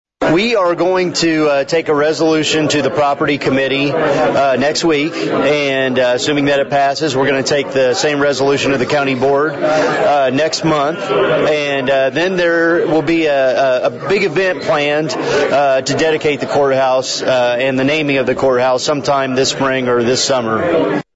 County Board Chairman Marron explained what happens next with the proposal.